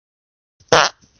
真实的屁 " 屁8
描述：真屁
Tag: 现实 放屁 真正